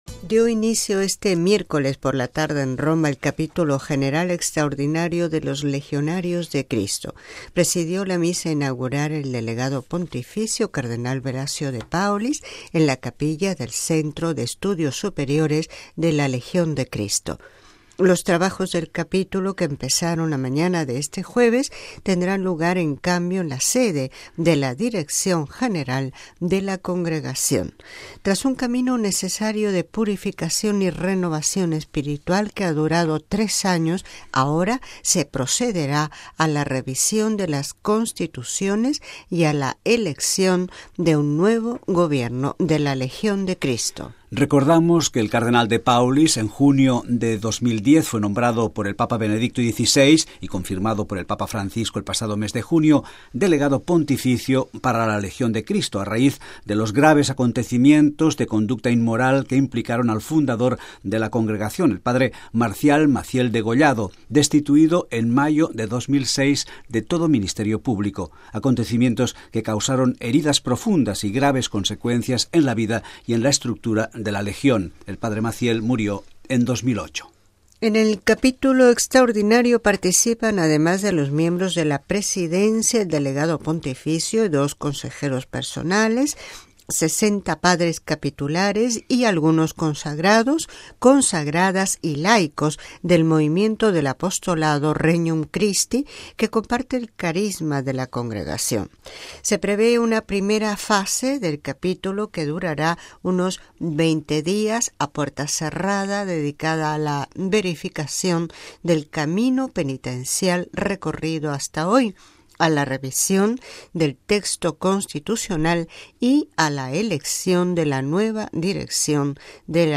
Texto completo de la entrevista concedida por el cardenal Velasio de Paolis al padre Federico Lombardi